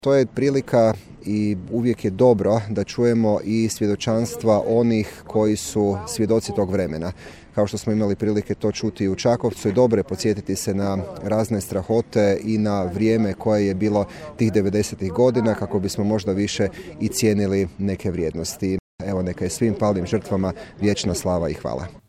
Diljem Vukovarske ulice upaljeni su lampioni, a počast braniteljima koji su život položili na oltar Domovine odana je kod spomen-obilježja smrtno stradalim i nestalim hrvatskim braniteljima Domovinskog rata Međimurske županije u Perivoju Zrinski.
Župan Matija Posavec: